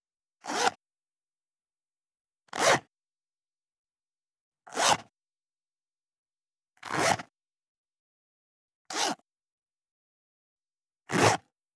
24.ジッパーを開ける【無料効果音】
ASMRジッパー効果音
ASMR